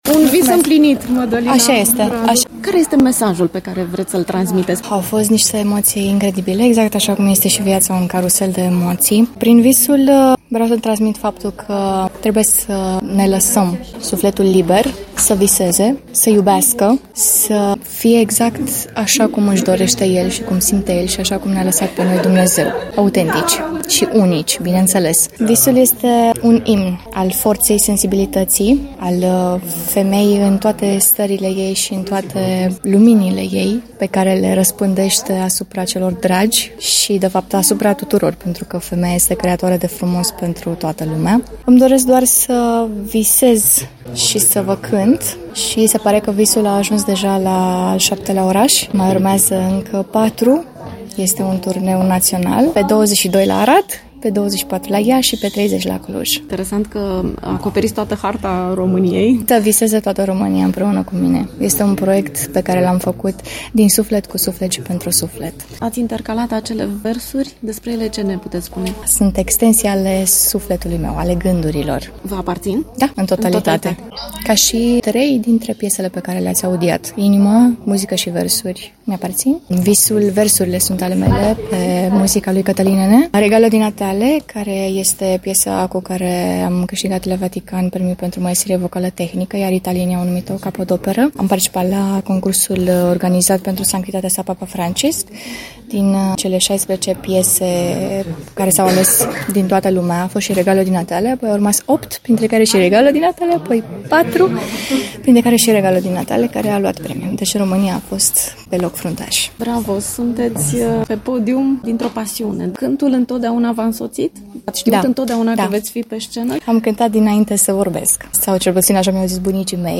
la finalul spectacolului de la Constanța, atunci când  „one woman”  a răspuns tuturor mesajelor de felicitare și cererilor de fotografii – amintire pentru o seară de neuitat!